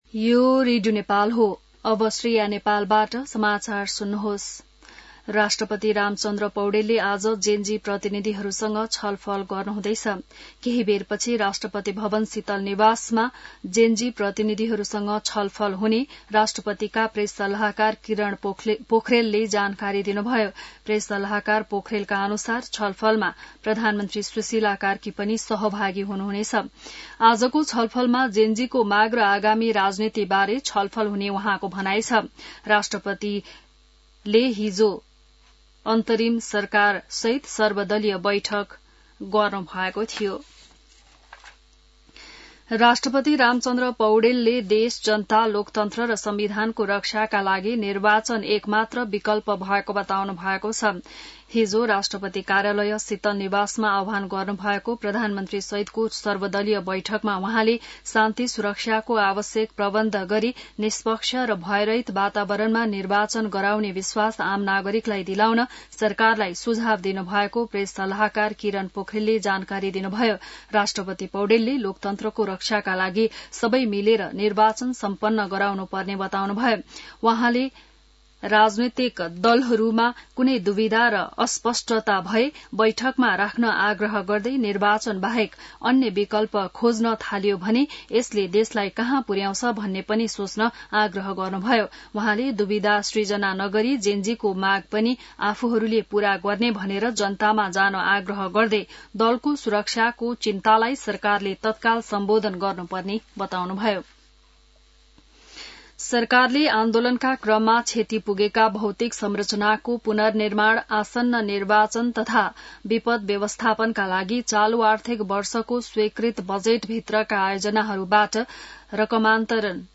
बिहान १० बजेको नेपाली समाचार : २५ असोज , २०८२